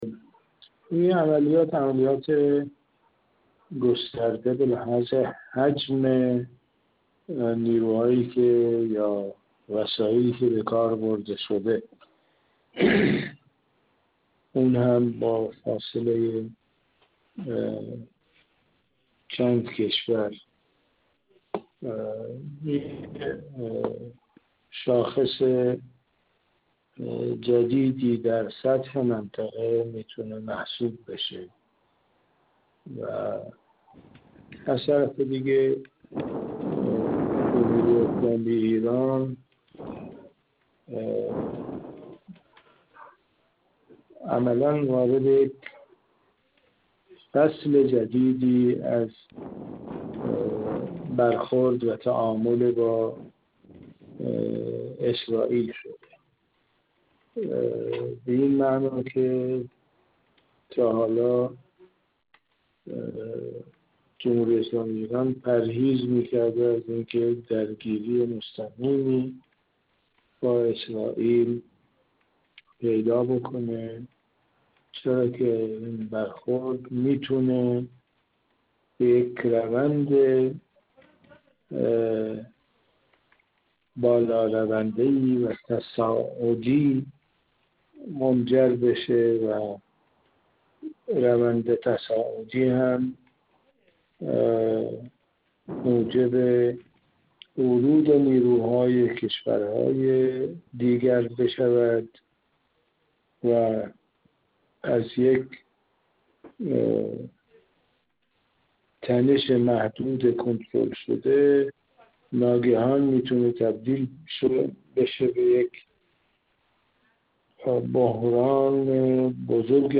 گفت‌وگو